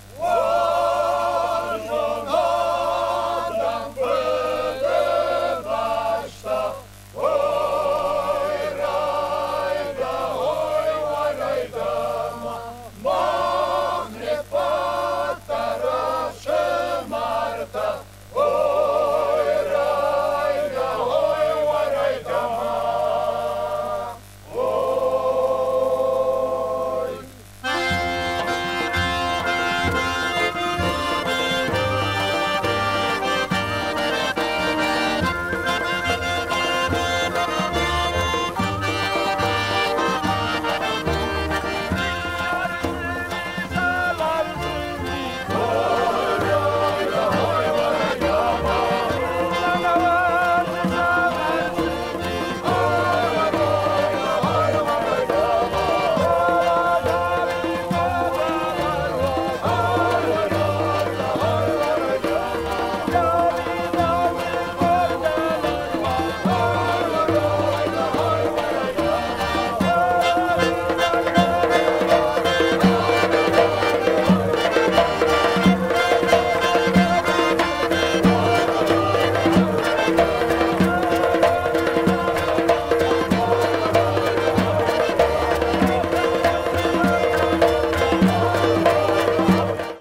※レコードの試聴はノイズが入ります。